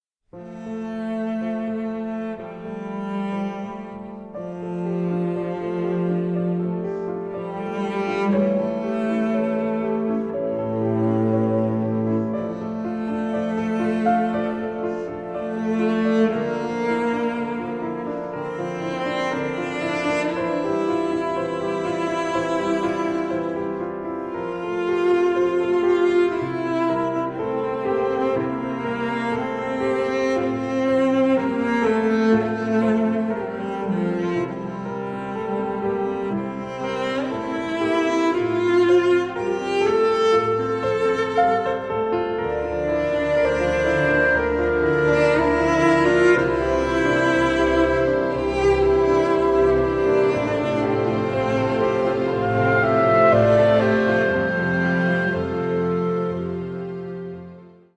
is more intimate and nuanced
a delicate and melancholic writing